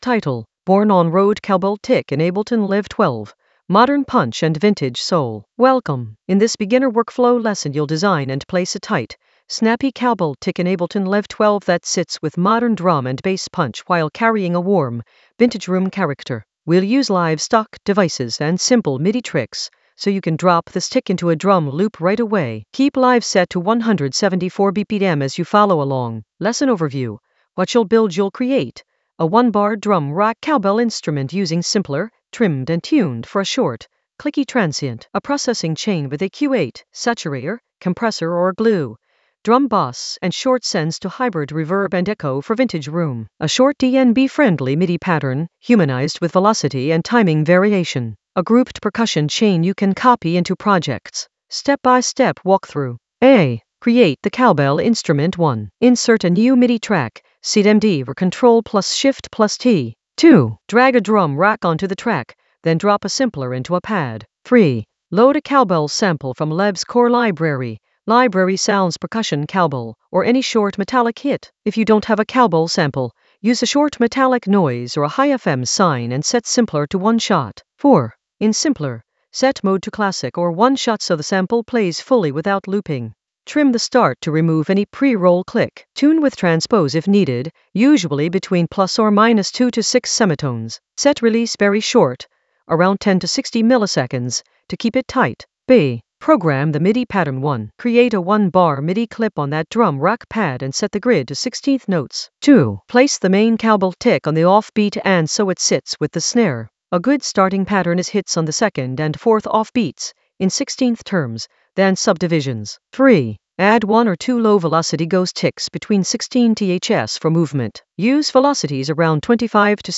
An AI-generated beginner Ableton lesson focused on Born on Road cowbell tick in Ableton Live 12 for modern punch and vintage soul in the Workflow area of drum and bass production.
Narrated lesson audio
The voice track includes the tutorial plus extra teacher commentary.